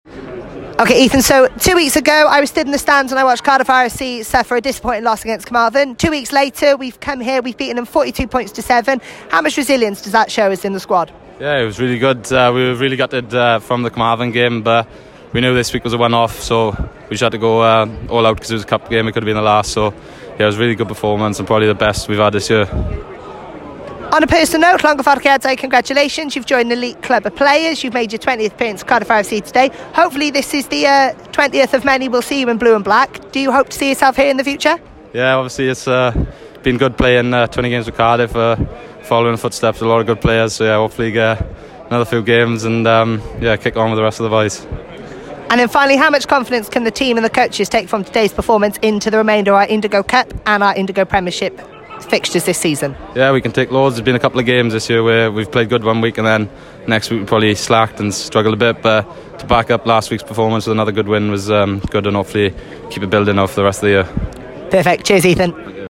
Post-Match Interviews